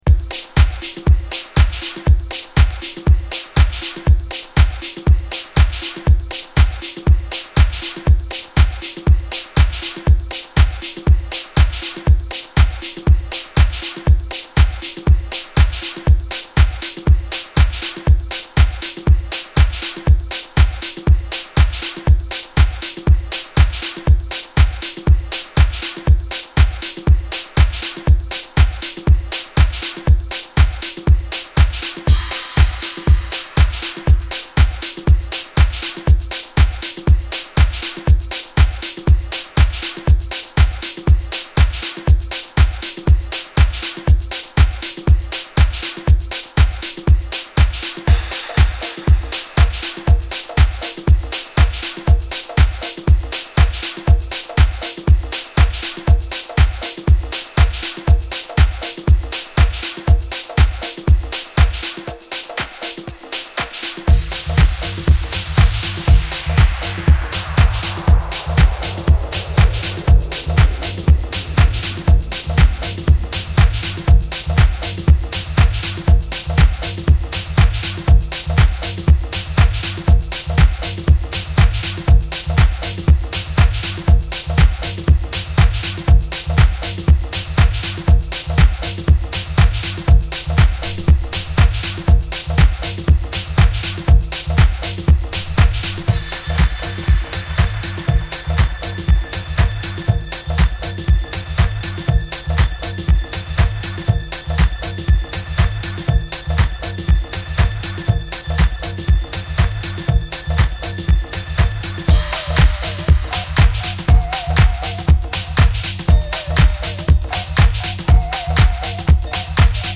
両サイド共にフロア仕様なナイスRemix！